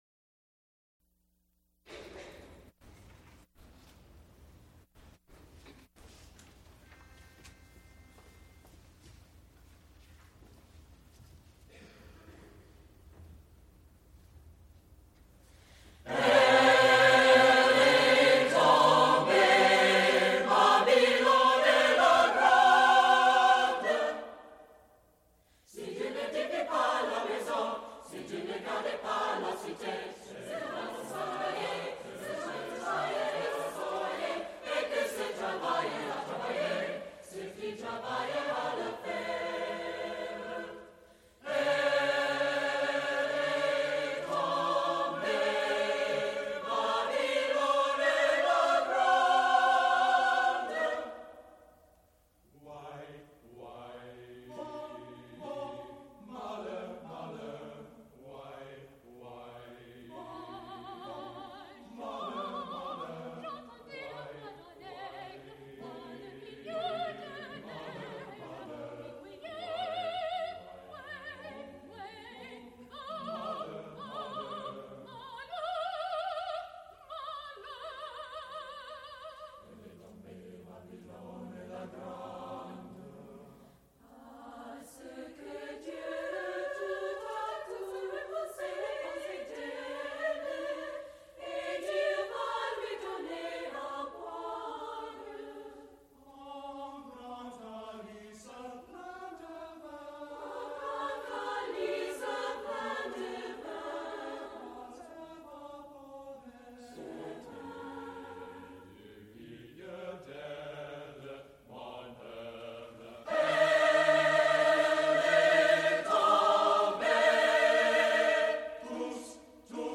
Vocal music
Recorded live November 13, 1977, Heinz Chapel, University of Pittsburgh.
Extent 2 audiotape reels : analog, half track, 7 1/2 ips ; 7 in.
Choruses, Secular (Mixed voices), Unaccompanied